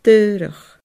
daorach /dɯːrəx/